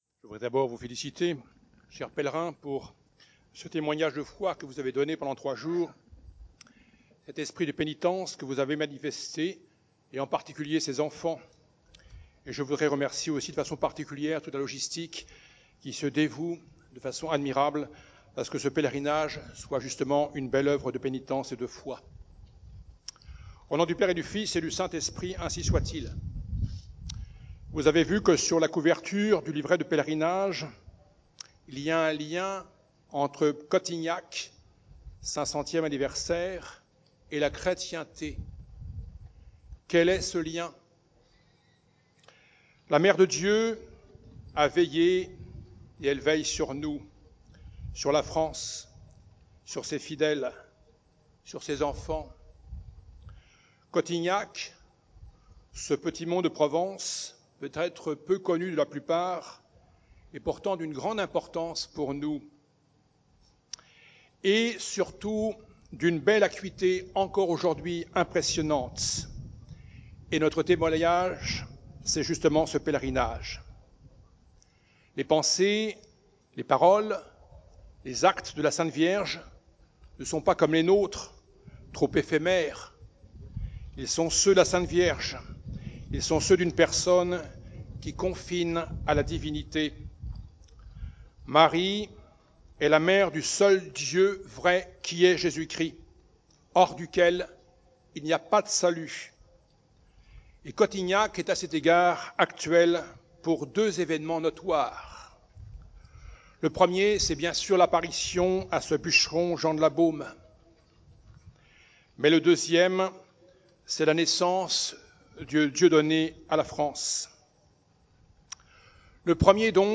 Pèlerinage de Pentecôte 2019 : le sermon